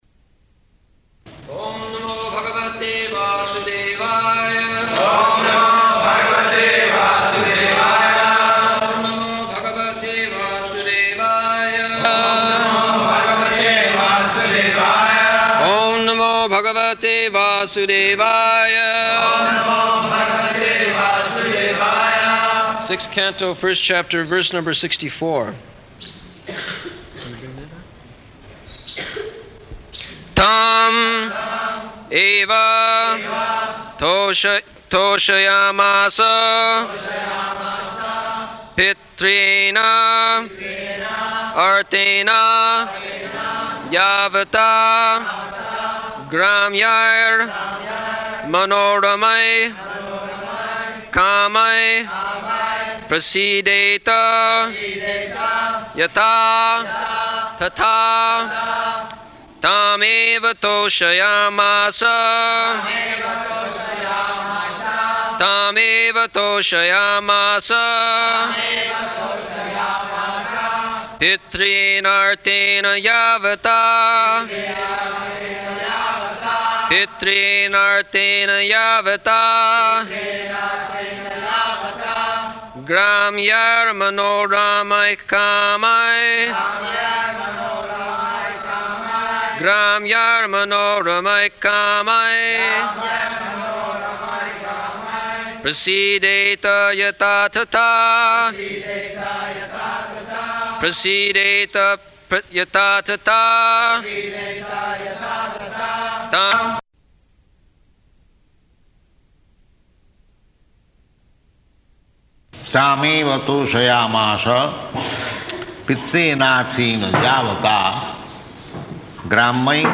September 1st 1975 Location: Vṛndāvana Audio file
[devotees repeat] Sixth Canto, First Chapter, verse number 64. [leads chanting of verse, etc]